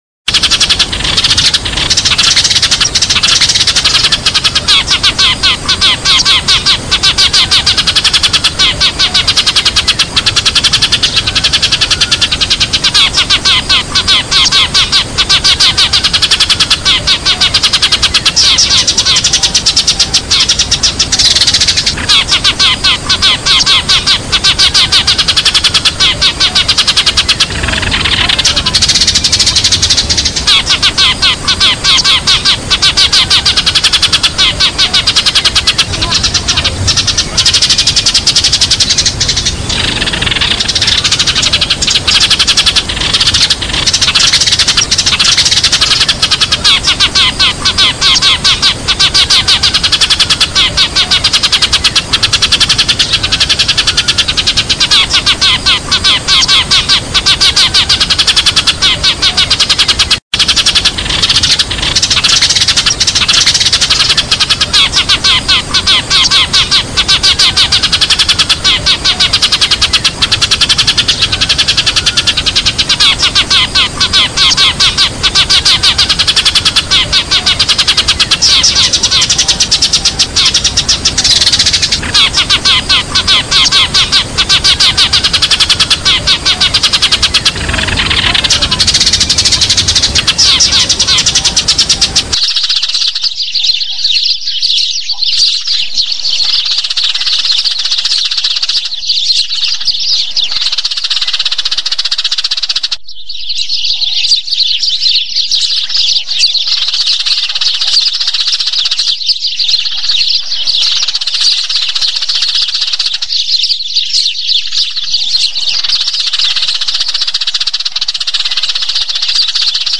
tieng chim se moi
Tieng-chim-se.mp3